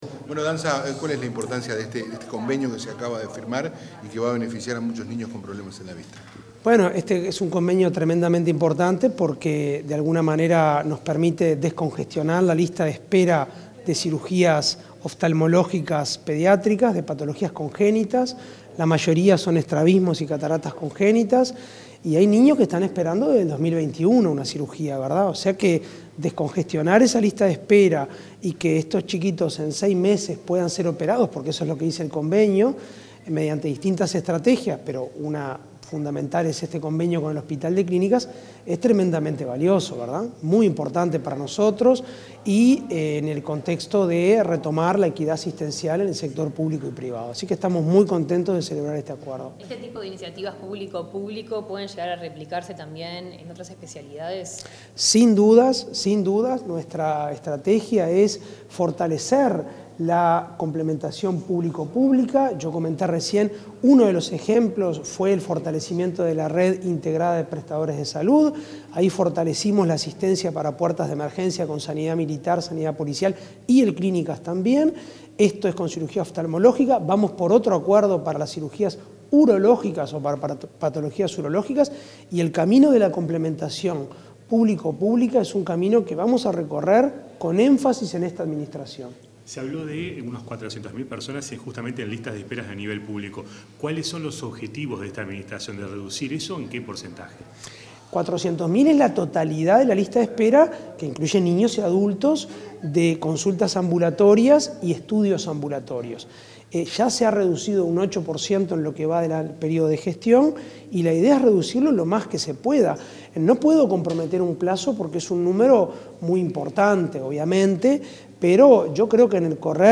Declaraciones del presidente de ASSE, Álvaro Danza